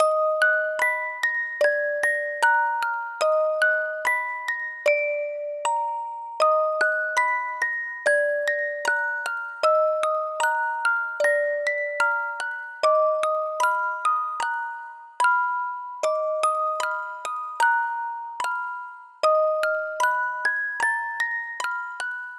HORROR MUSIC BOX / Loop